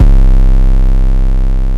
YM - Spinz 808 4.wav